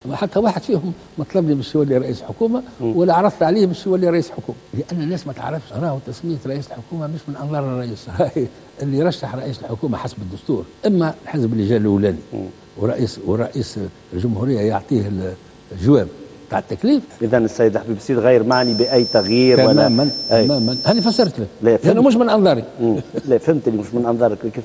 وعلق قايد السبسي قائلا في مقابلة مع التلفزيون الرسمي "الوطنية"، إنه غير معني بتسمية رئيس حكومة جديد، موضحا أن اجتماعاته الأخيرة مع وزراء سابقين هي لمجرد استشارتهم بحكم خبرتهم المعروفة وخاصة في المجال الاقتصادي بخصوص الوضع الحالي في تونس.